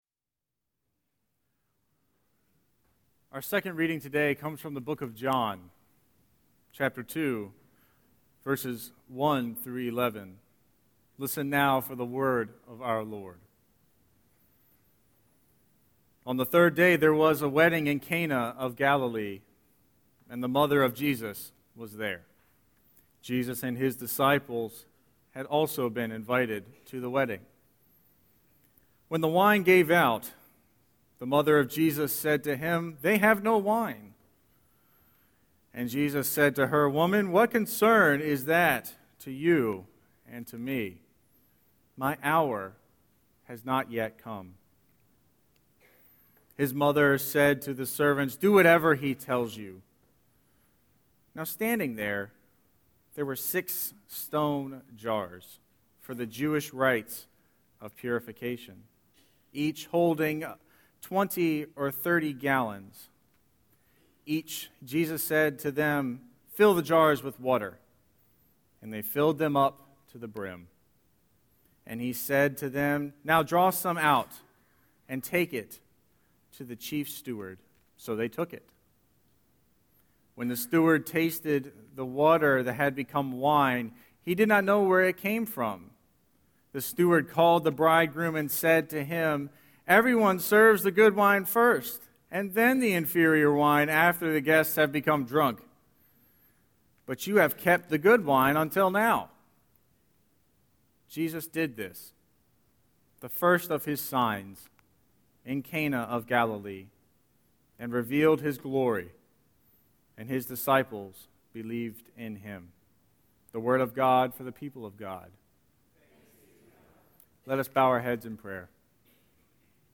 01-17-Scripture-and-Sermon.mp3